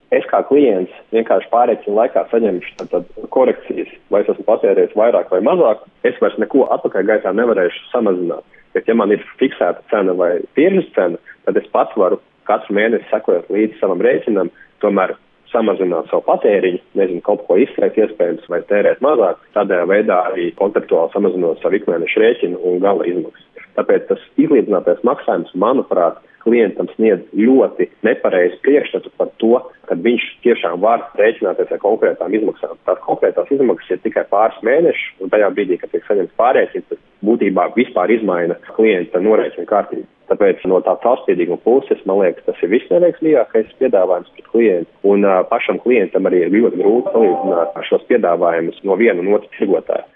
Министр экономики Илзе Индриксоне (Национальный союз) заявила, что такие счета предназначены для тех жителей, у которых так называемая выравненный платеж.